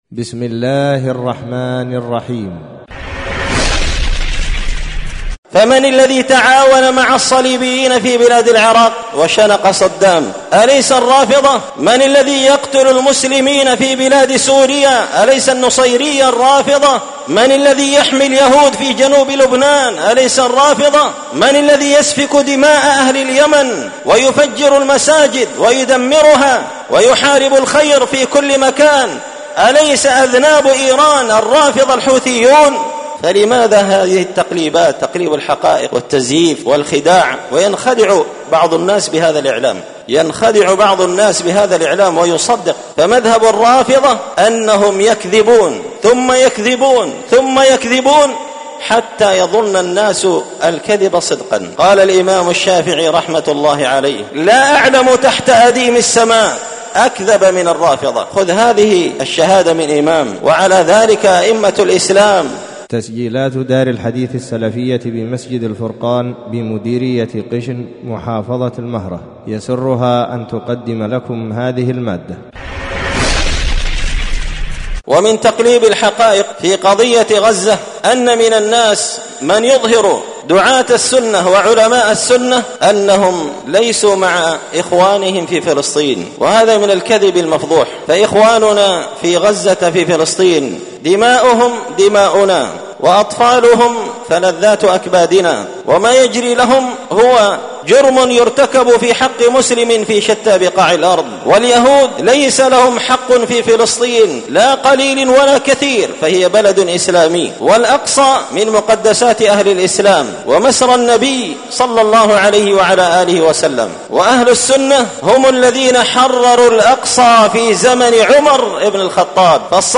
خطبة جمعة بعنوان:
ألقيت هذه الخطبة بدار الحديث السلفية بمسجد الفرقان قشن-المهرة-اليمن تحميل